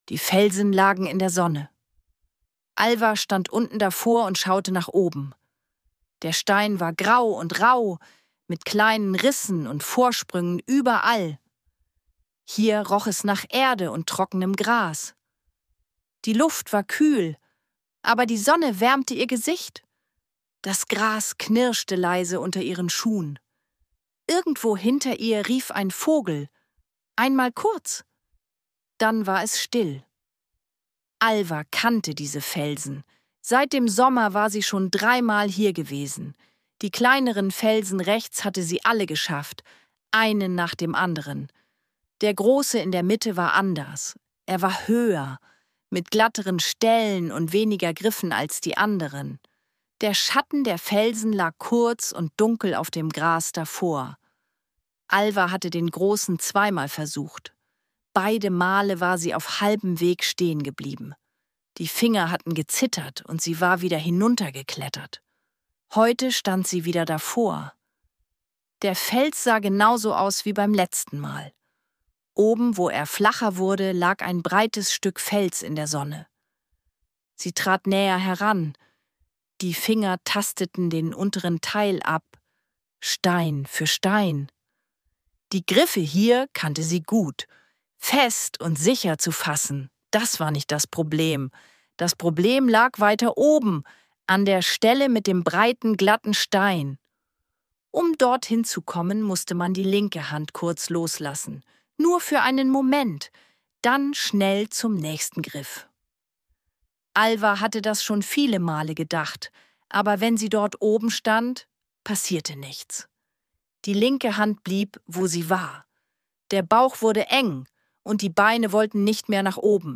Eine ruhige Abenteuergeschichte für Kinder über Mut, Zweifel und das Loslassen alter Wege.
Ruhige Kindergeschichten zum Anhören